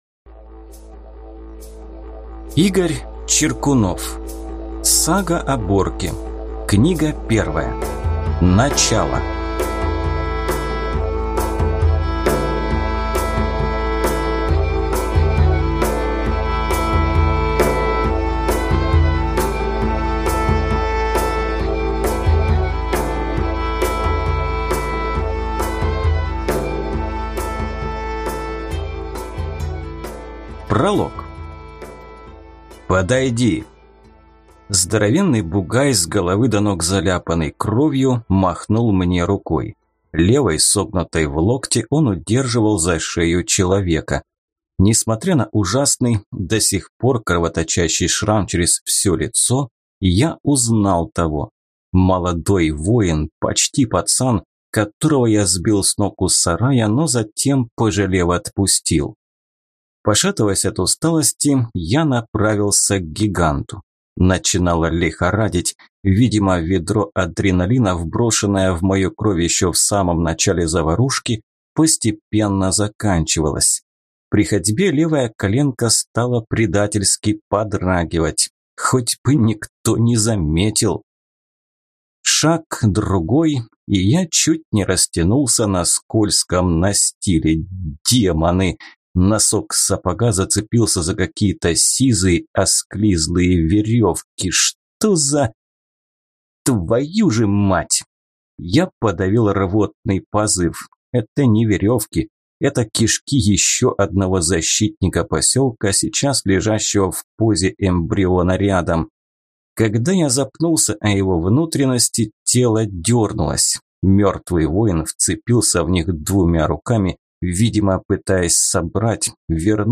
Аудиокнига Начало | Библиотека аудиокниг